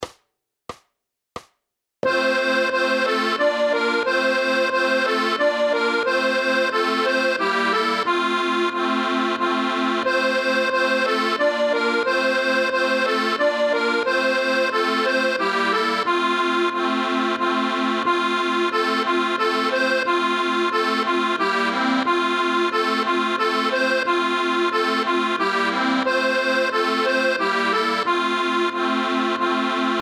Formát Akordeonové album
Hudební žánr Vánoční písně, koledy